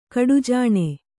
♪ kaḍujāṇe